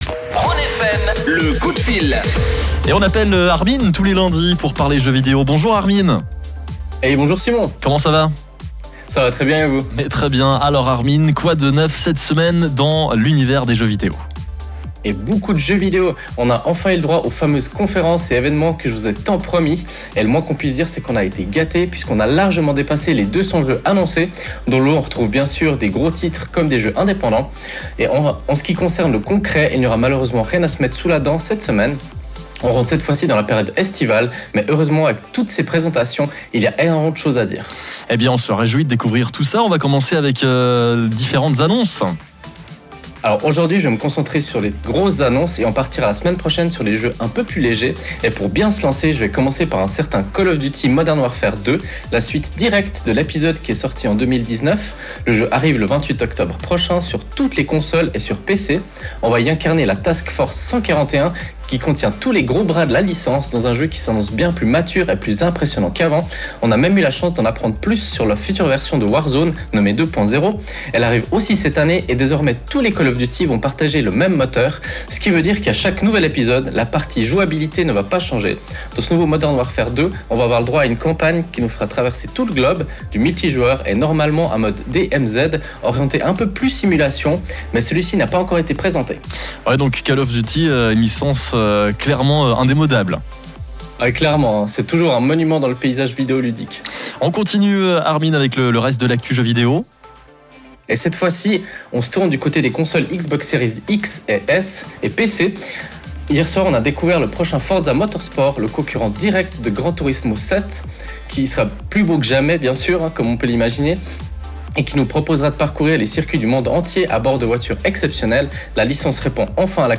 Comme tous les lundis, nous vous proposons une chronique gaming sur la radio Rhône FM.